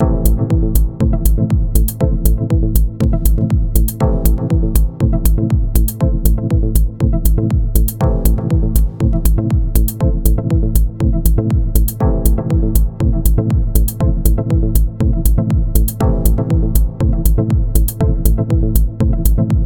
You can hear the crackles here (ideally with headphones— they might be less noticeable on laptop or desktop speakers).
They start somewhere in the middle of the first or second bar and then occur every two bars at the beginning of the bar, likely because that’s where multiple notes are triggered.
That said, it’s still fewer than 12 voices playing simultaneously.
in this example, no multisamples were involved - synth engine presets only, also no tape deck, no punch effects.